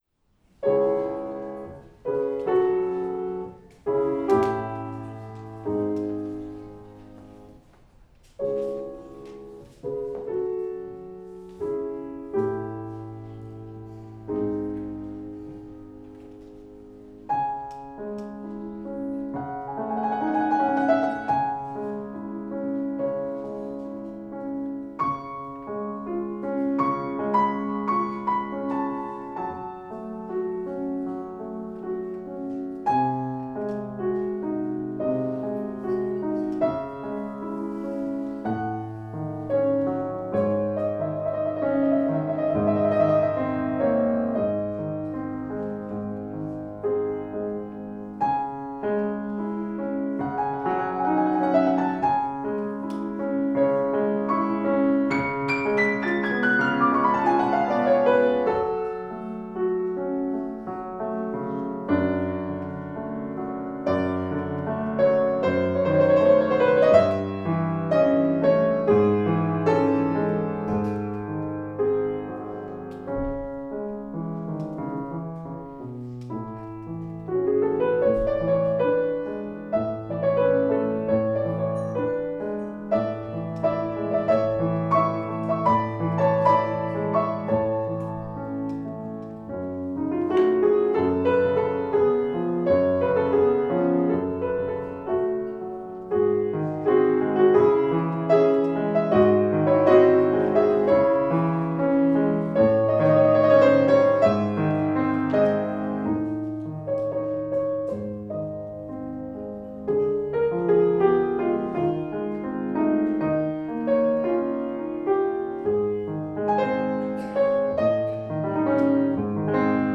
2024-01-13 Concert of Polish Music - Songs and Instrumental Works/ fortepian